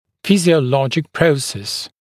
[ˌfɪzɪə’lɔʤɪk ‘prəuses][ˌфизиэ’лоджик ‘проусэс]физиологический процесс